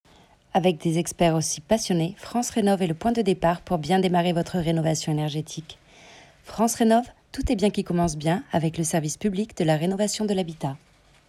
Voix off
Bandes-son